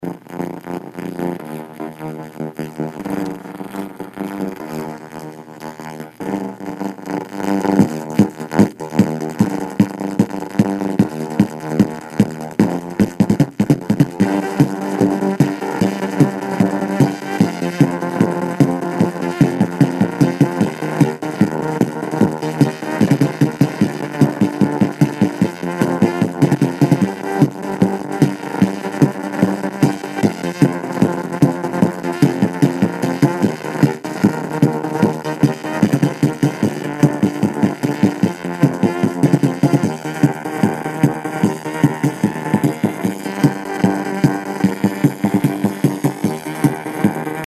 Distorção no som do FM.
A distorção é bem característica, o FM soa parecido com um "pato engasgado". E o volume destoa bastante do PSG, já que este MSX é considerado um dos melhores no nível da mixagem do som do FM e PSG. Ouça como fica o FM com os capacitores defeituosos,
UR_demo,_capacitor_Defeituoso_No_XDJ.mp3